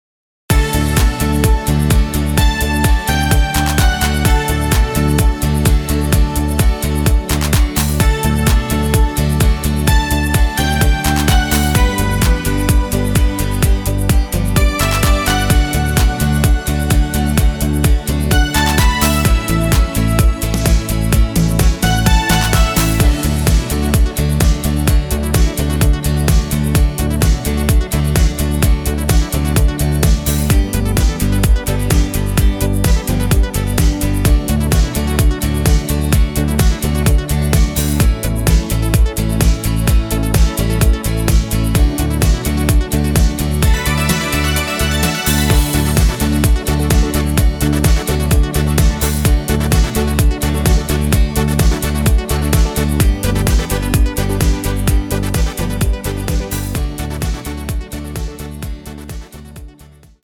leichter zu singende Tonarten
easy to sing